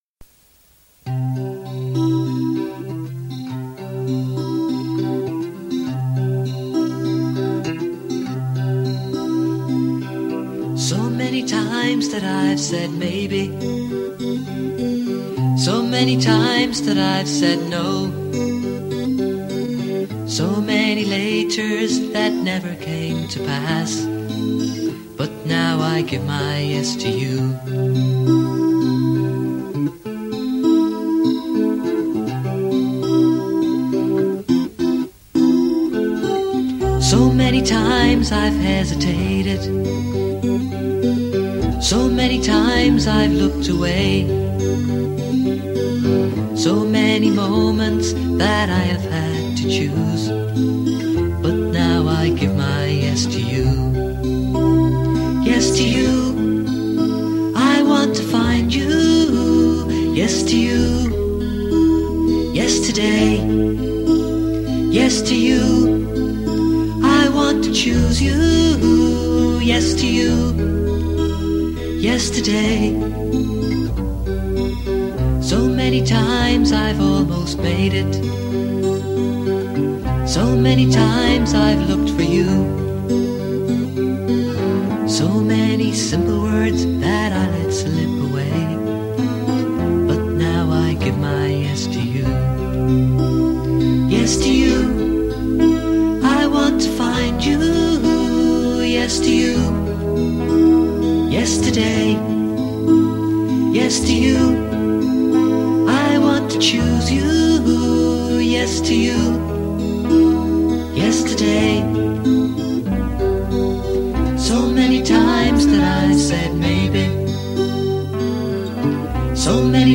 Basse qualité